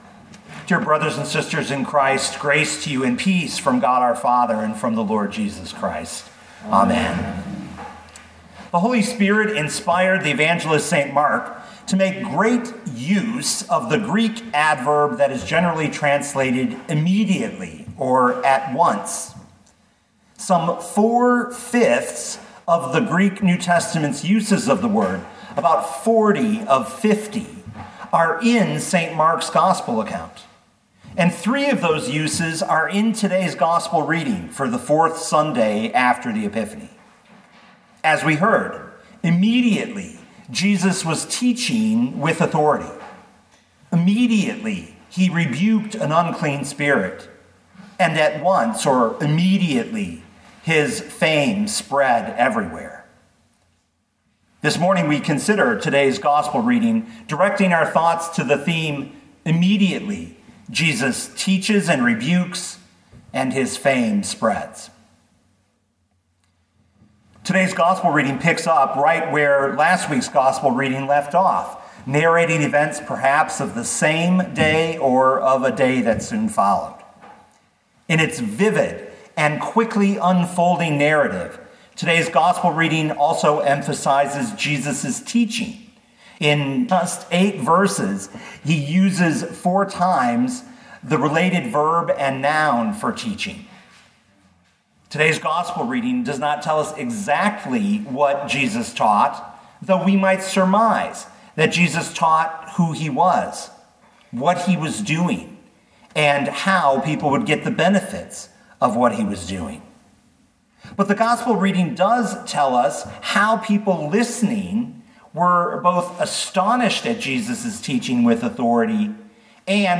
2021 Mark 1:21-28 Listen to the sermon with the player below, or, download the audio.